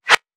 metahunt/weapon_bullet_flyby_15.wav at master
weapon_bullet_flyby_15.wav